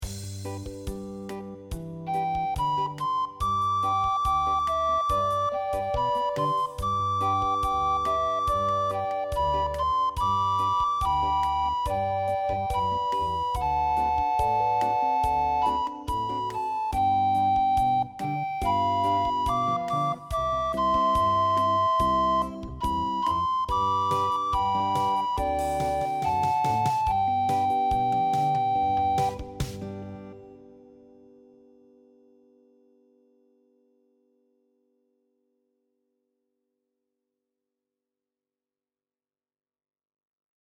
Recorder Songbook
Duet